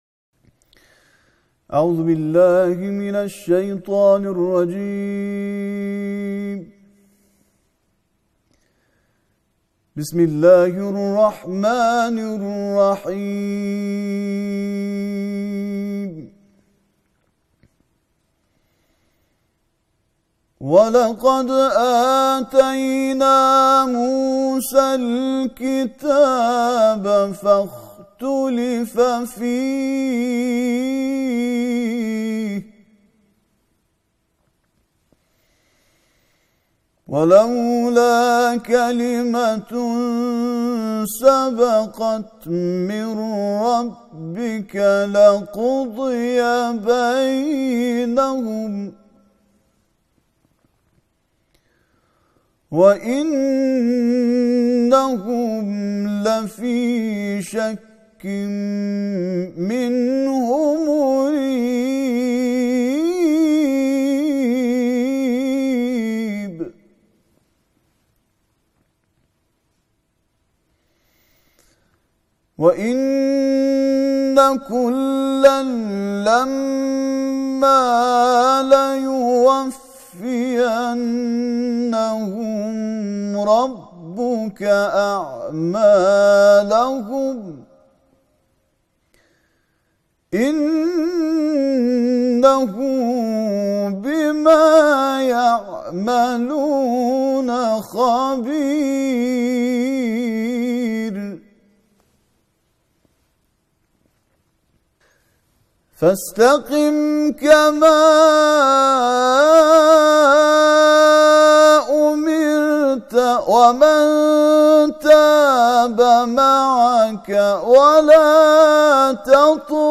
به گزارش ایکنا، همزمان با ایام ماه مبارک رمضان، طرح ۳۰ کرسی تلاوت قرآن در ۳۰ شب ماه بهار قرآن به صورت مجازی و با حضور قاریان ممتاز و بین‌المللی از سوی فرهنگ‌سرای قرآن اجرا شد.